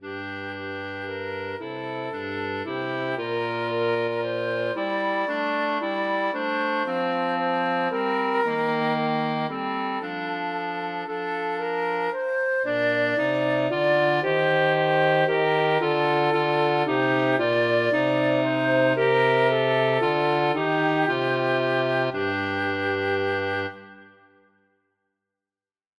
Vánoční hudba barokních kancionálů